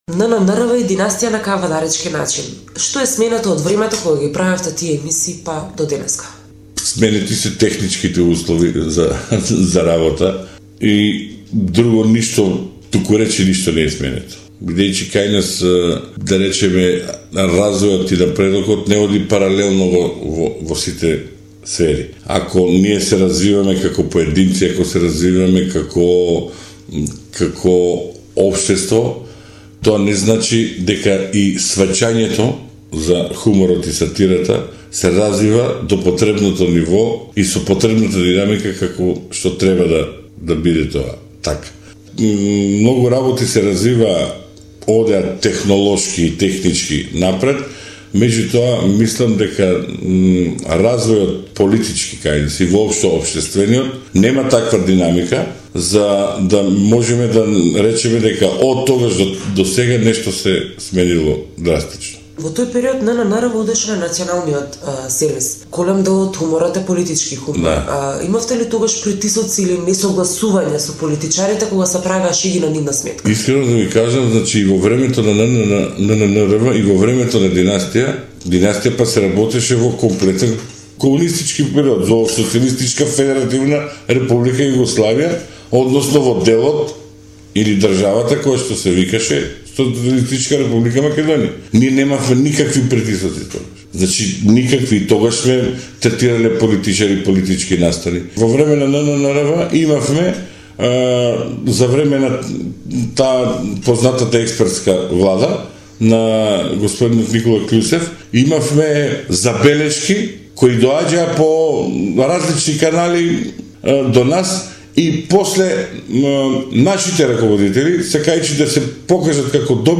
Интервју со Љупчо Бубо Каров од К-15